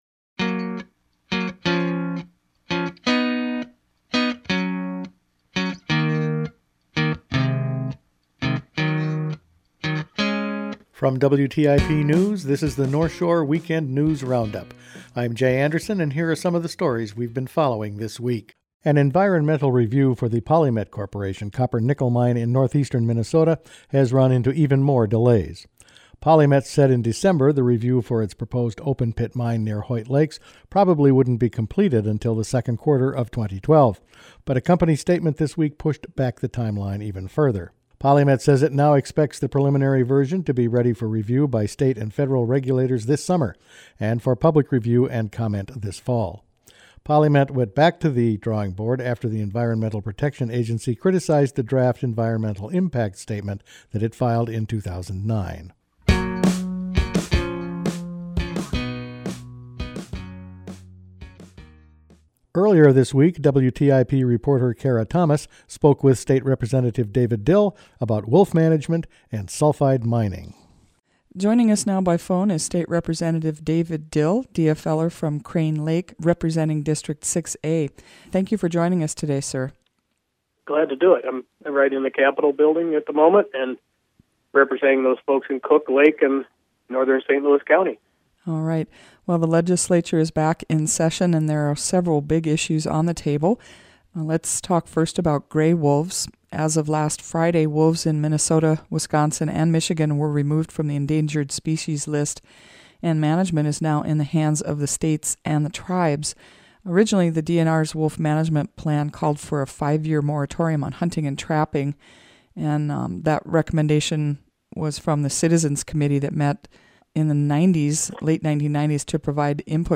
Weekend News Roundup for Feb. 4